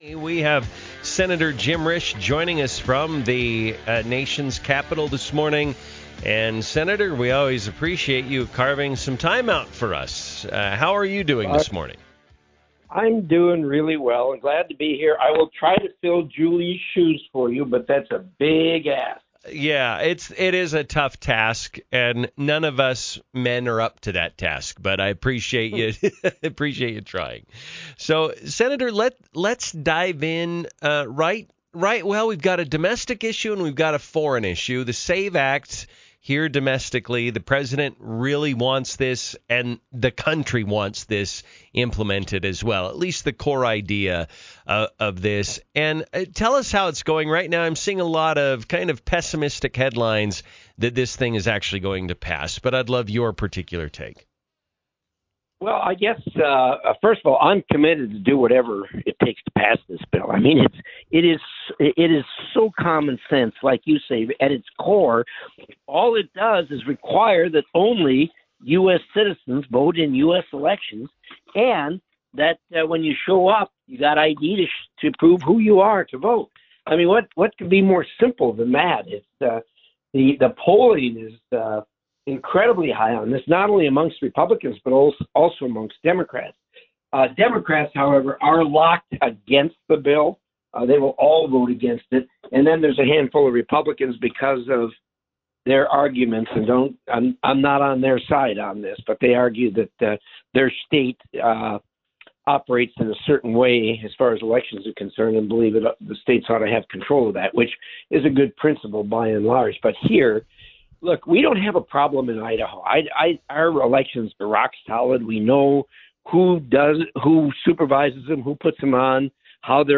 INTEREVIEW: Sen. Jim Risch on SAVE Act, Iranian Conflict - Newstalk 107.9